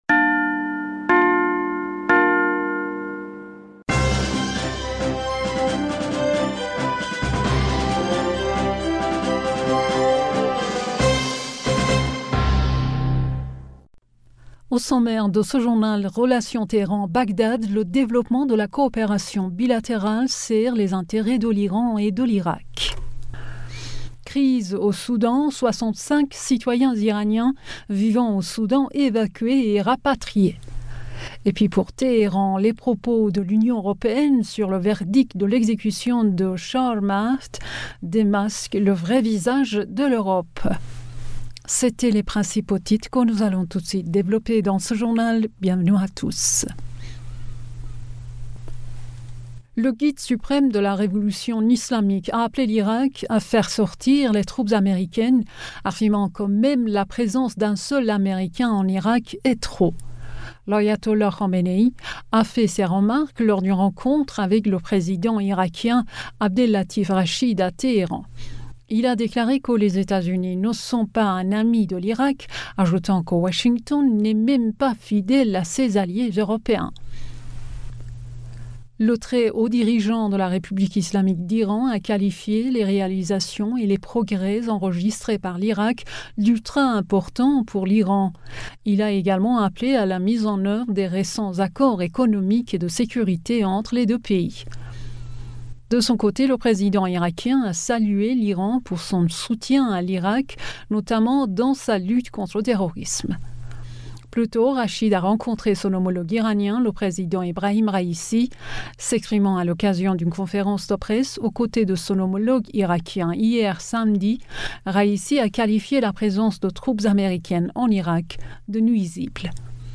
Bulletin d'information du 30 Avril 2023